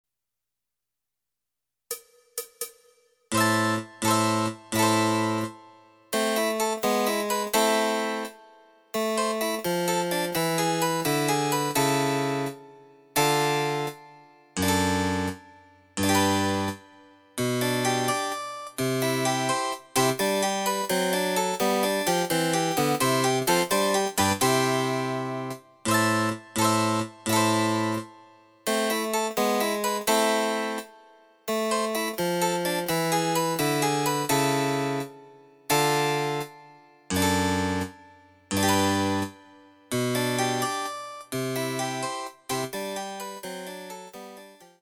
その他の伴奏
第４楽章　極端に遅い (2)
Electoric Harpsichord